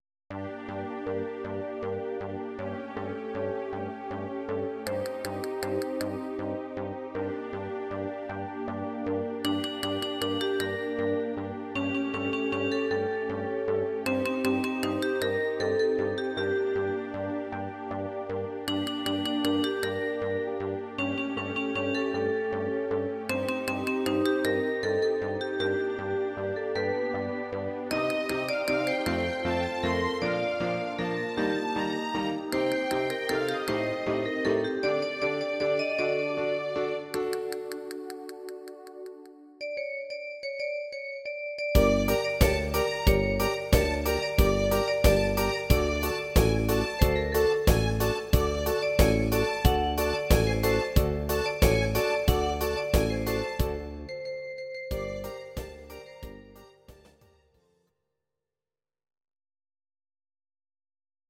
Audio Recordings based on Midi-files
Ital/French/Span, 2000s